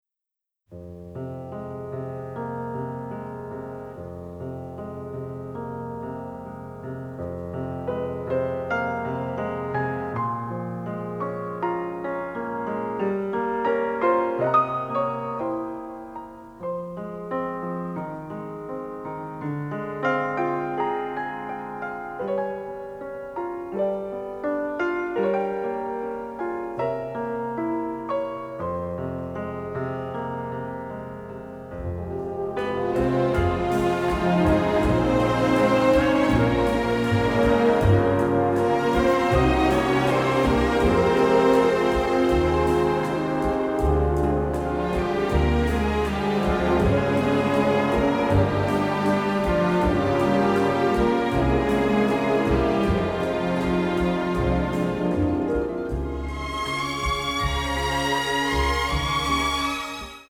The Soundtrack Album (stereo)